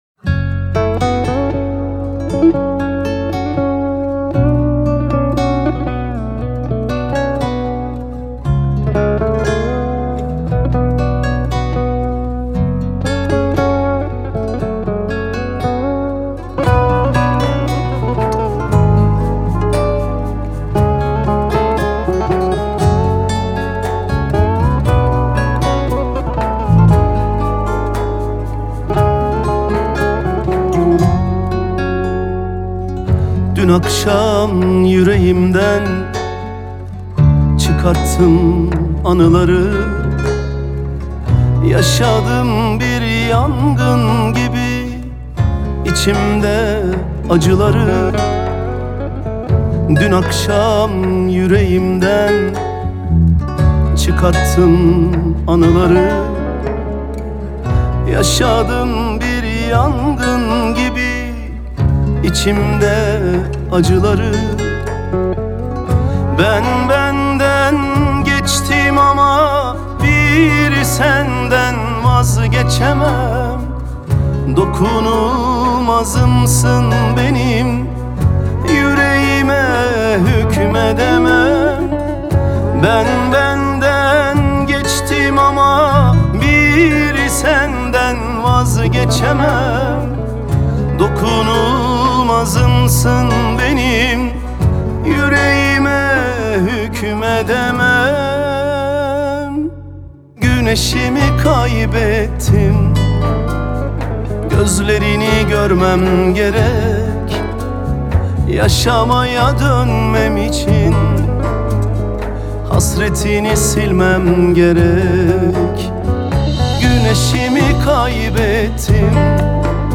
دانلود آهنگ غمگین ترکی استانبولی – ۳۷